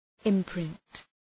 Προφορά
{ım’prınt}